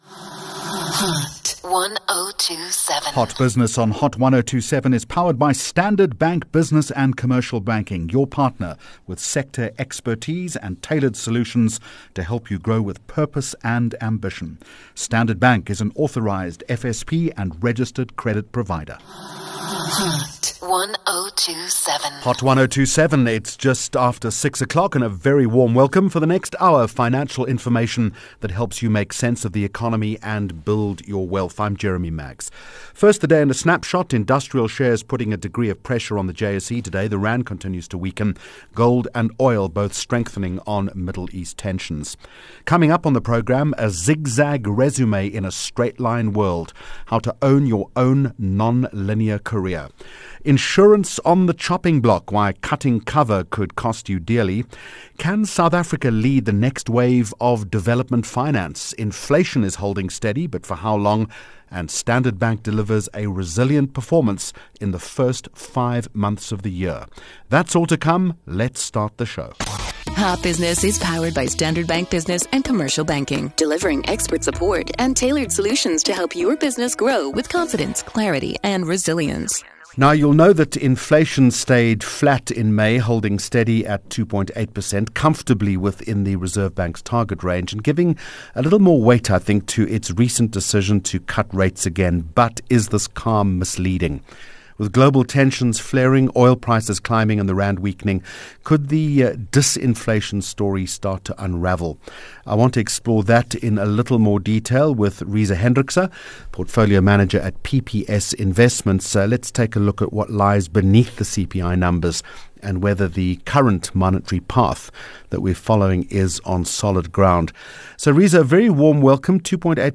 19 Jun Hot Business Interview